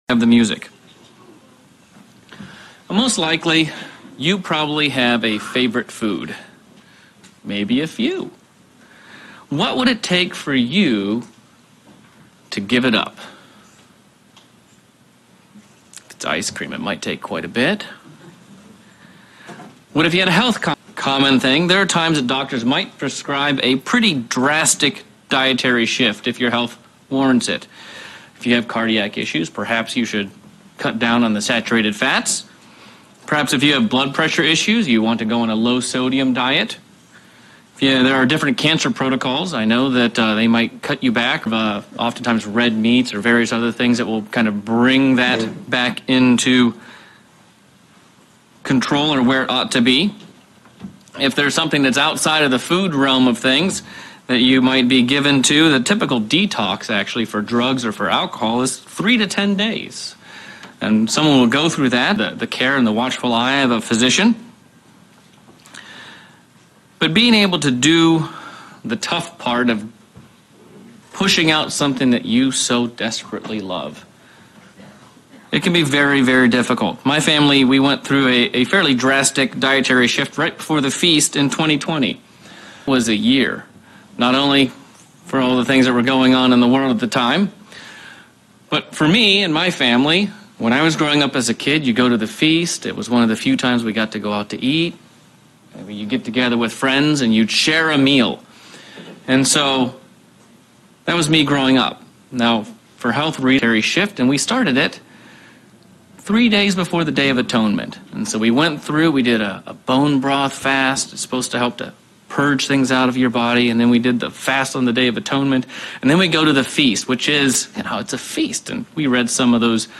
First Day of Unleavened Bread sermon focusing on the things we can do to follow our Father's prescription to remove sin.